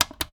Buttons Colletions Demo
switch_5.wav